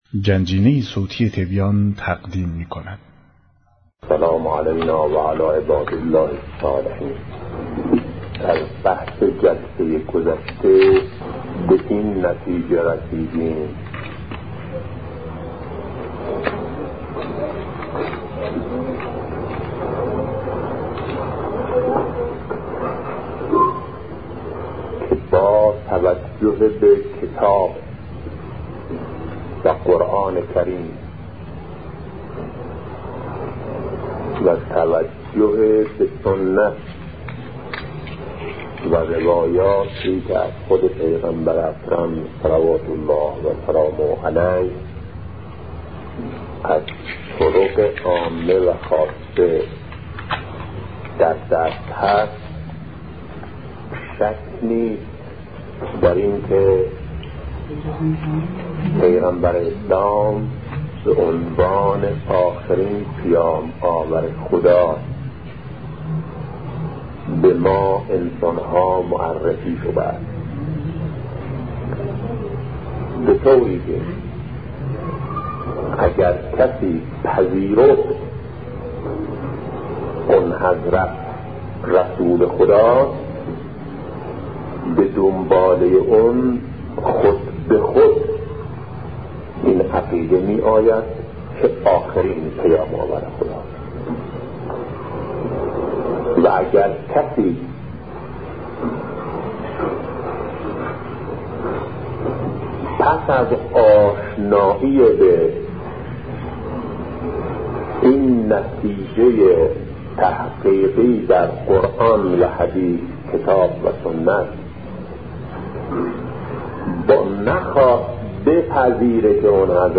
سخنرانی شهید بهشتی(ره) - با موضوع حقیقت خاتمیت- بخش‌سوم